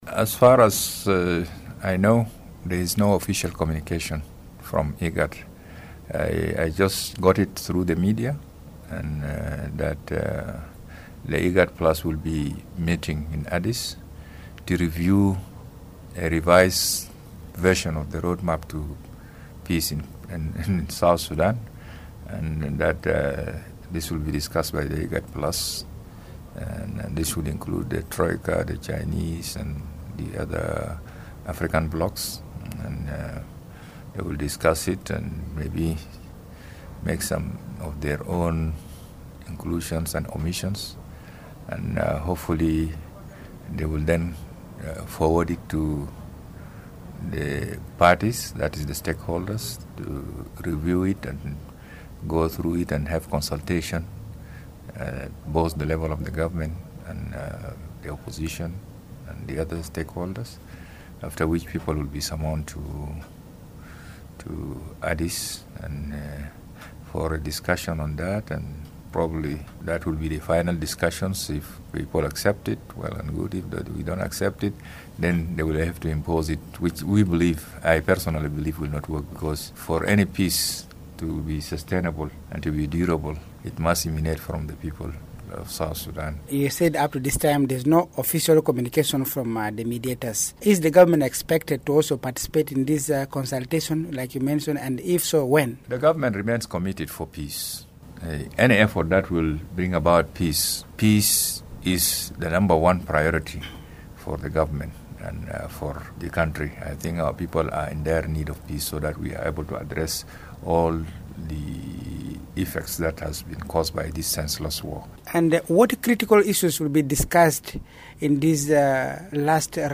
In an exclusive interview with Radio Miraya, Peter Bashir Gbandi said solutions imposed onto the people will only lead to complications.